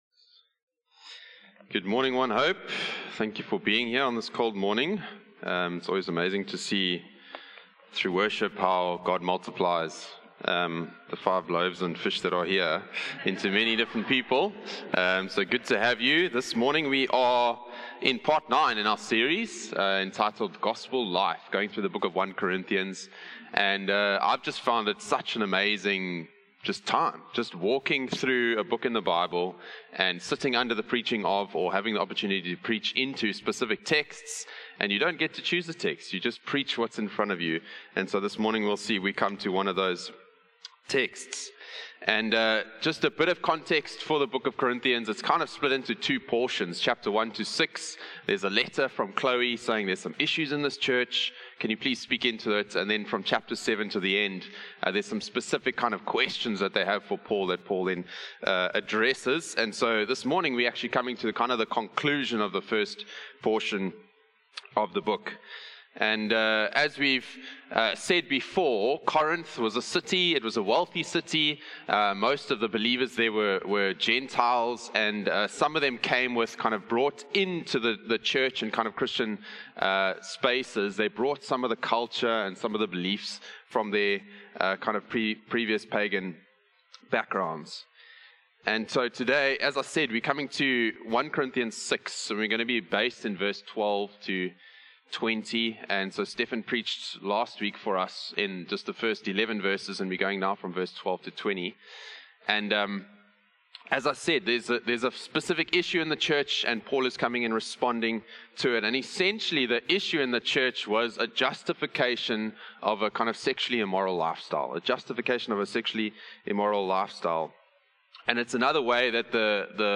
A message from the series "Gospel Life."